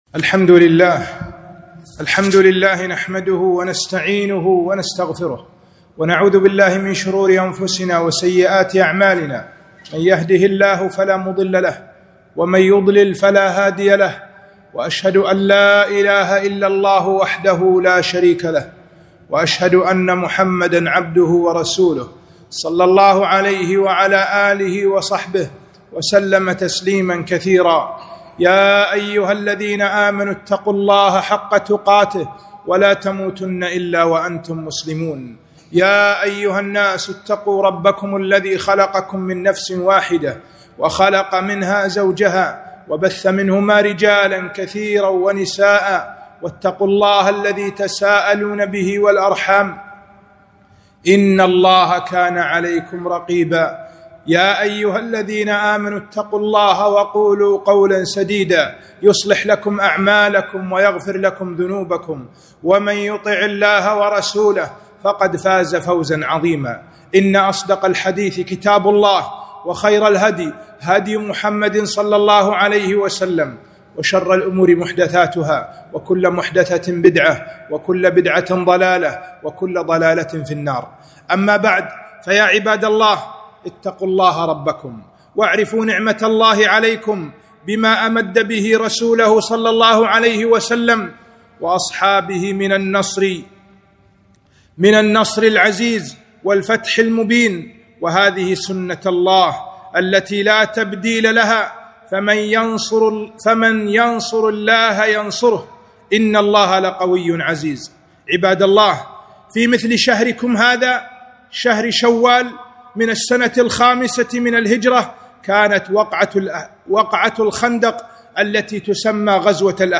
خطبة - دروس من غزوة الخندق